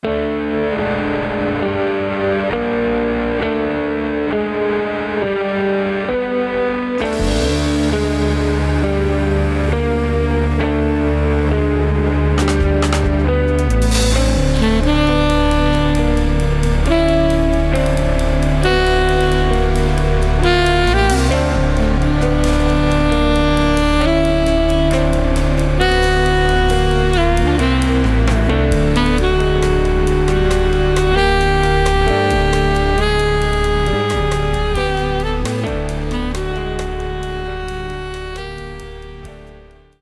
alto flute